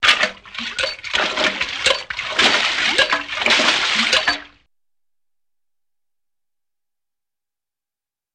На этой странице собраны звуки работы водяных насосов разных типов.
Качают воду ручным насосом